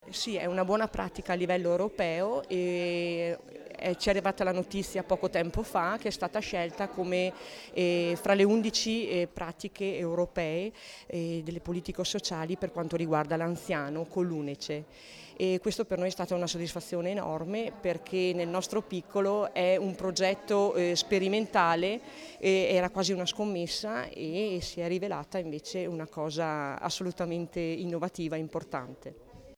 Riprese, immagini e interviste a cura dell'Ufficio Stampa -